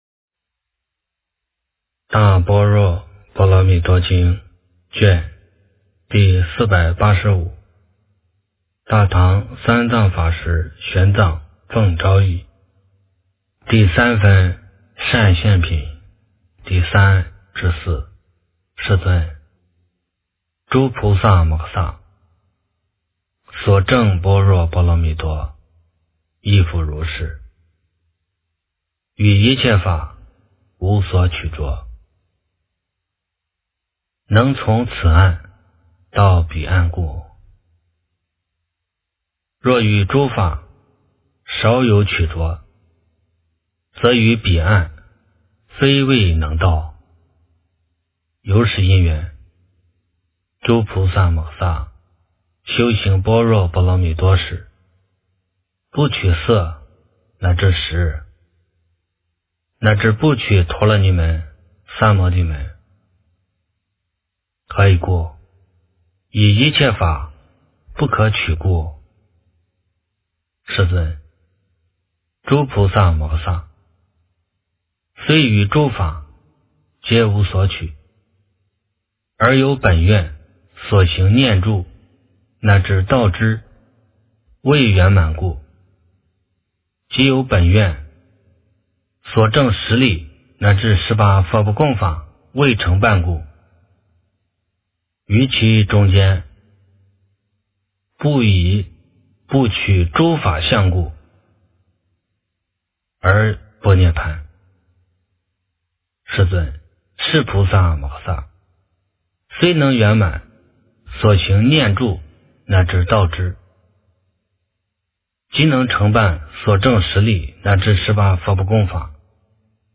大般若波罗蜜多经第485卷 - 诵经 - 云佛论坛